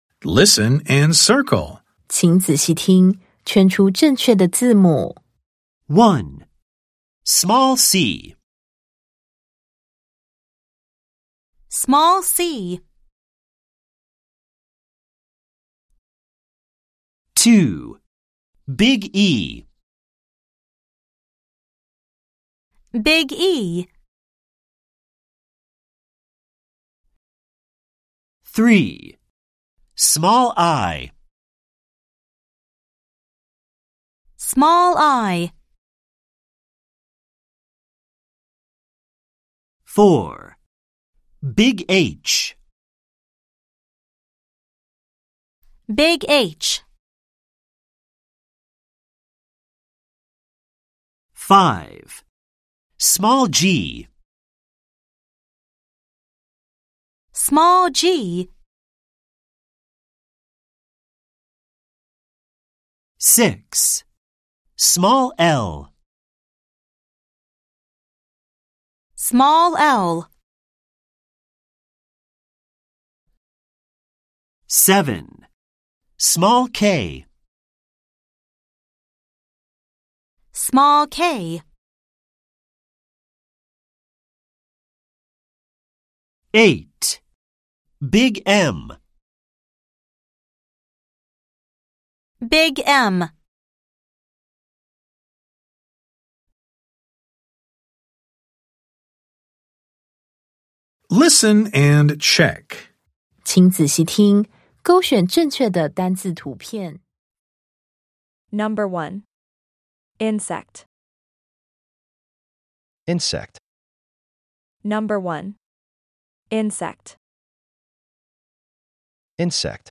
第3次定期評量_英語聽力測驗.mp3